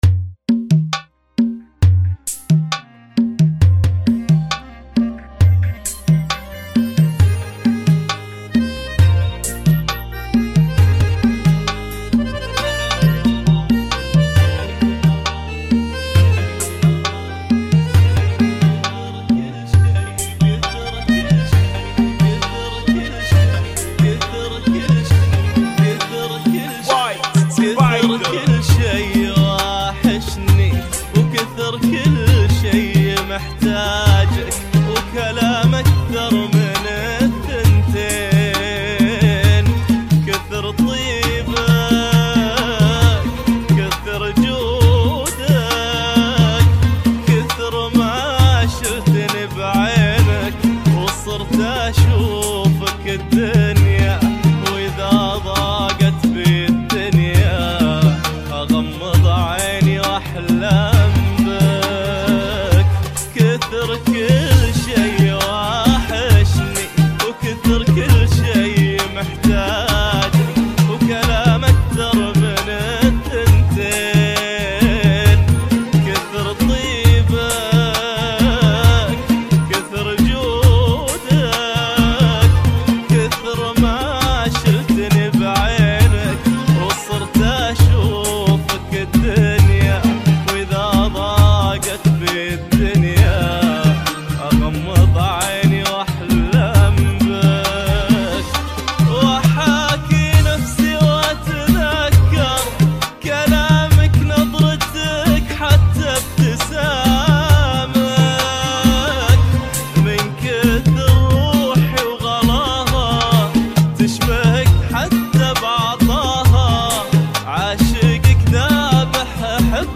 Funky [ 67 Bpm ]